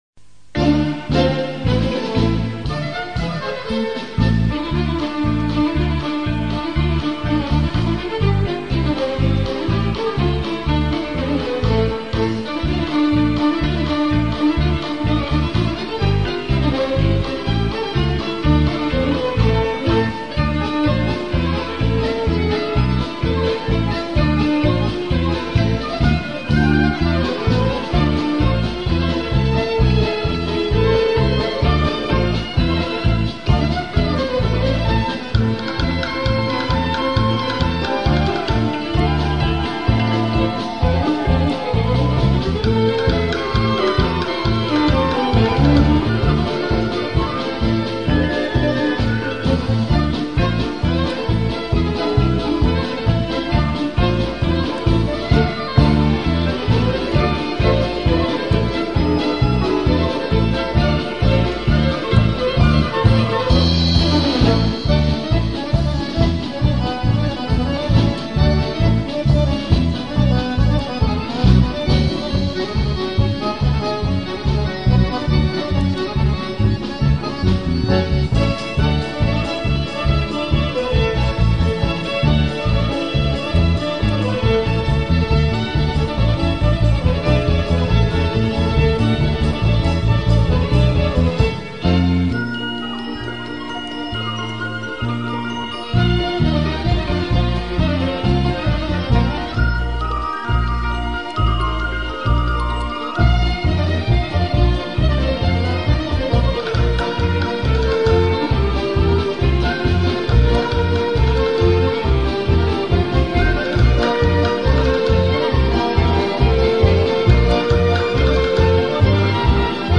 Гэта ўсе адбываецца пад музыку аднайменнай песні.